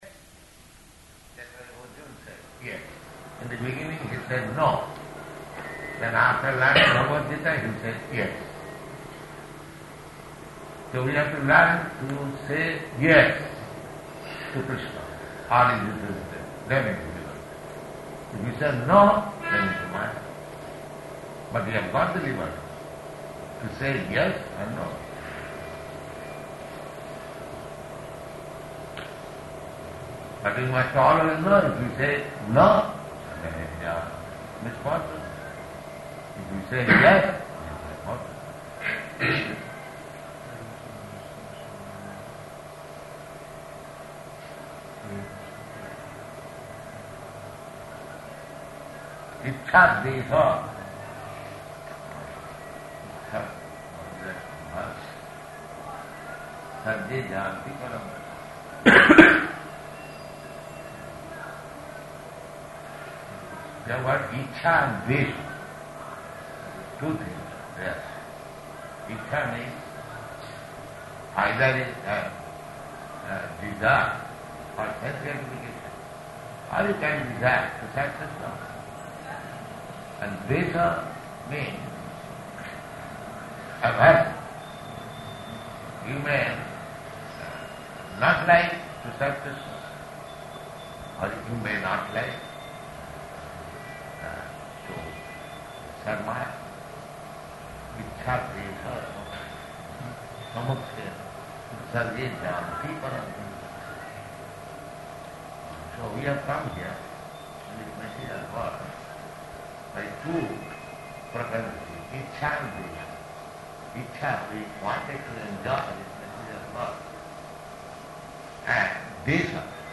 Room Conversation
Location: Delhi